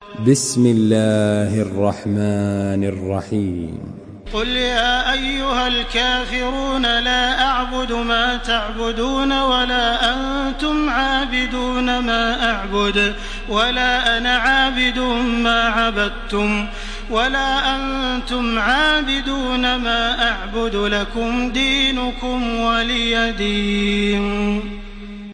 Surah Al-Kafirun MP3 by Makkah Taraweeh 1429 in Hafs An Asim narration.
Murattal Hafs An Asim